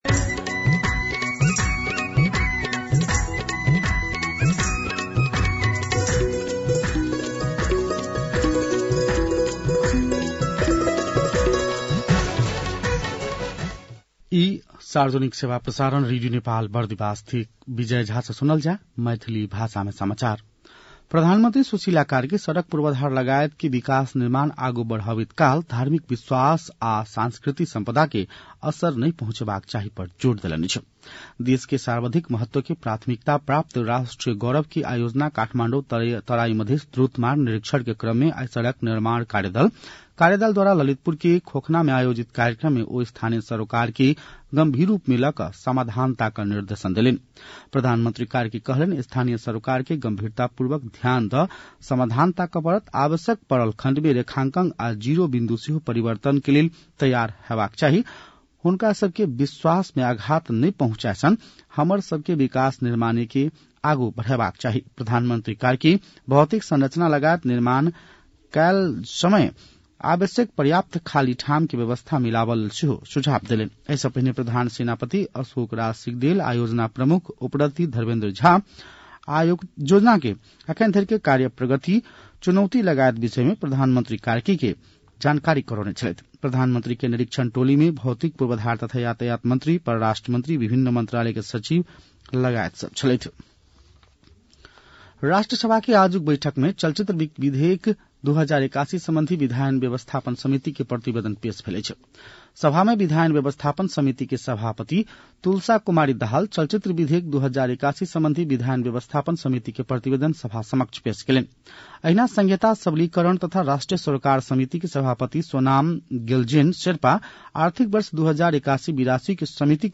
मैथिली भाषामा समाचार : १३ माघ , २०८२